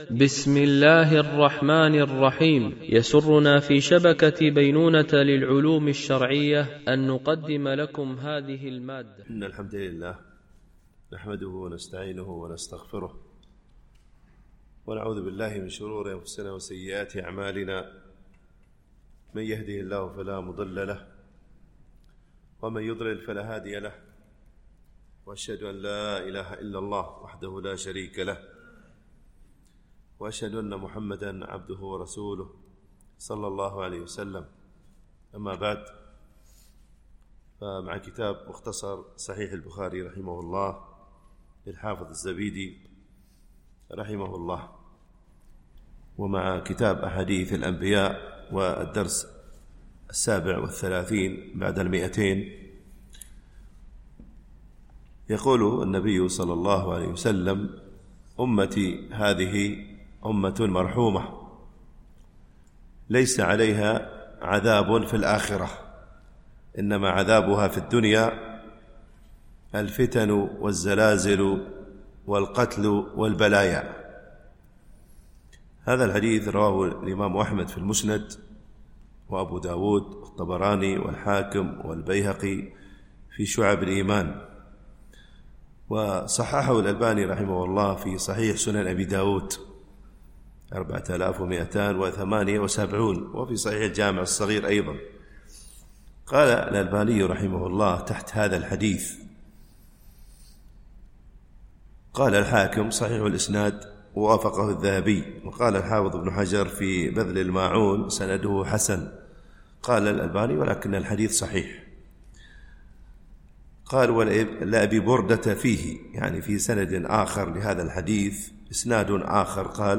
شرح مختصر صحيح البخاري ـ الدرس 237 ( كتاب أحاديث الأنبياء - الجزء السابع عشر - الحديث 1470 )